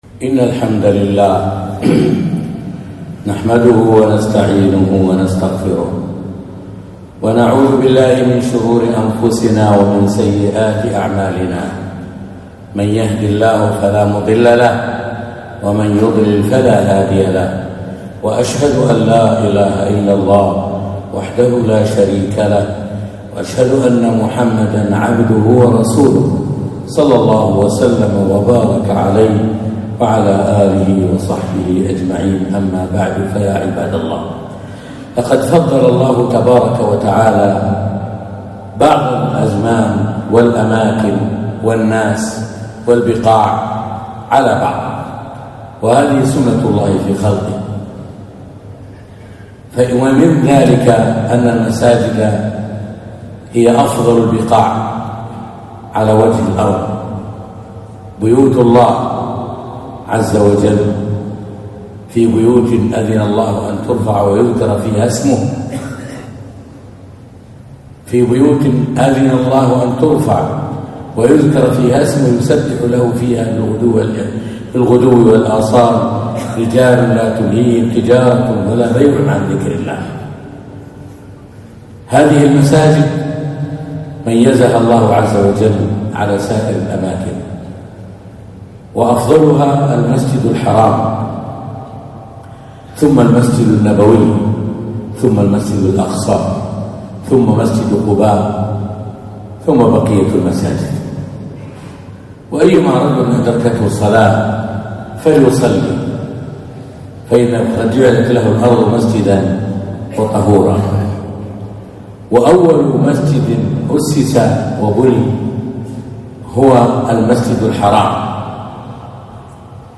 خطبة - أهمية العناية بالمساجد